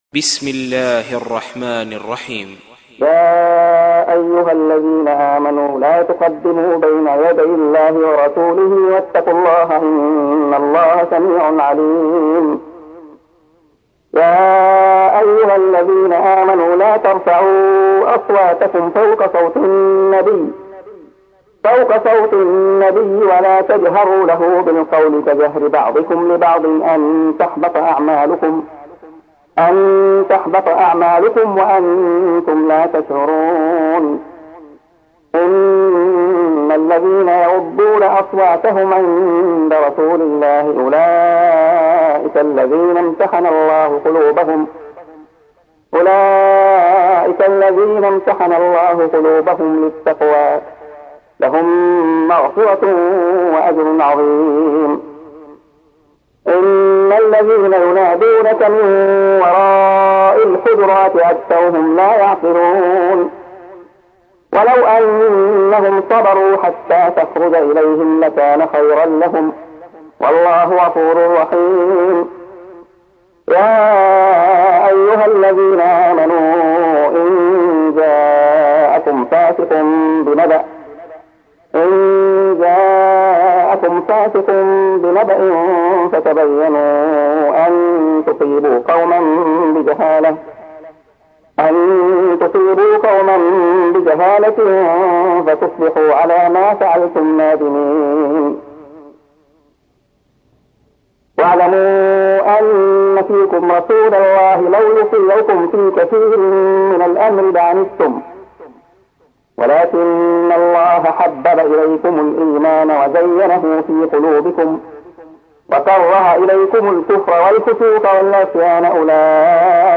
تحميل : 49. سورة الحجرات / القارئ عبد الله خياط / القرآن الكريم / موقع يا حسين